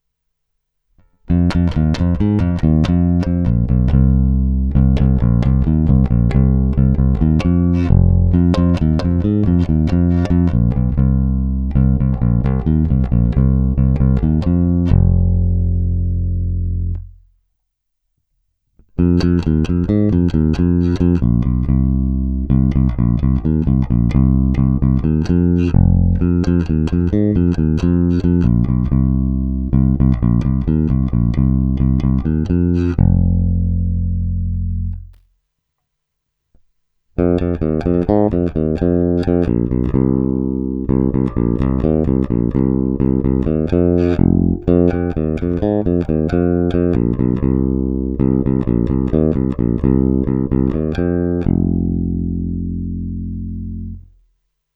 Zvuk je naprosto klasický Jazz Bass, zvláště v pasívním režimu.
Není-li uvedeno jinak, následující nahrávky jsou provedeny rovnou do zvukové karty, jen normalizovány, jinak ponechány bez úprav.